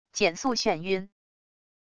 减速眩晕wav音频